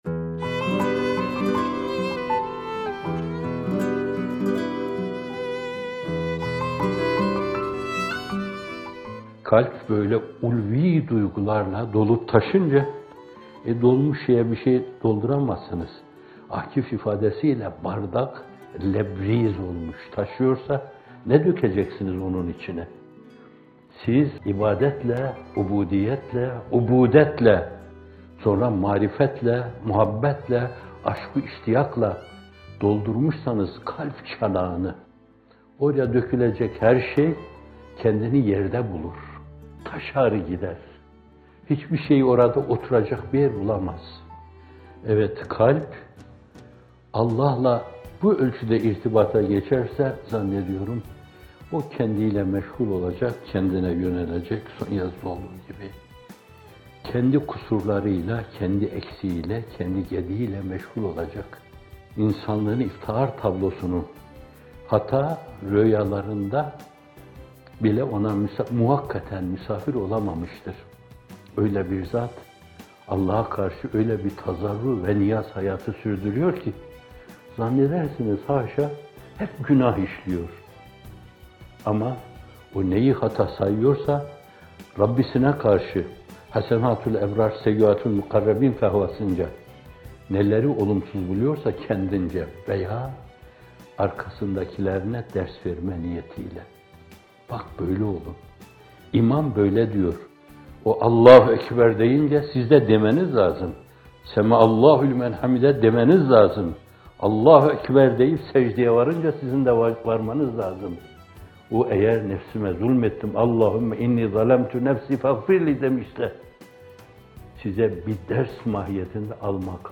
Bir Nefes (73) – Kalbi Ulvî Şeylerle Doldurma - Fethullah Gülen Hocaefendi'nin Sohbetleri